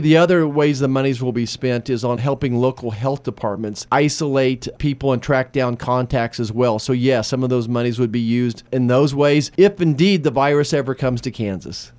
Marshall, in an interview with KMAN Friday said money from the bill will also help support local governments respond to the disease.